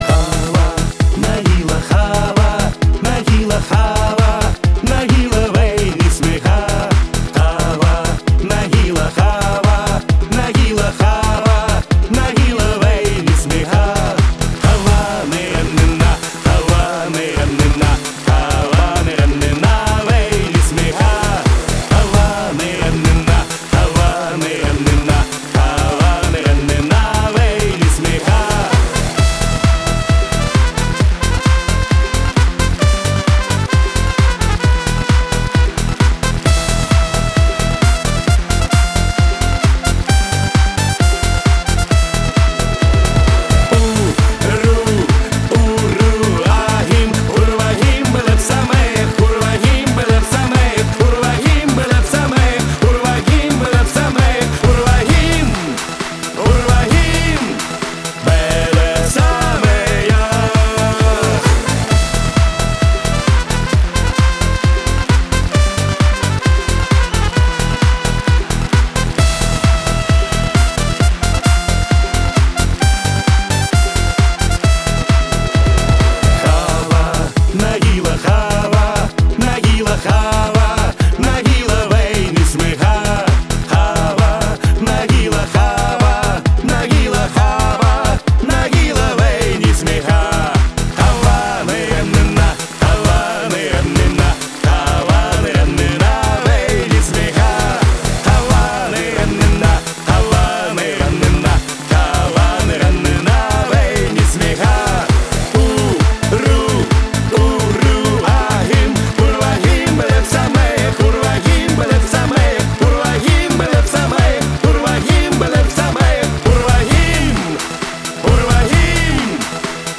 dance
Еврейские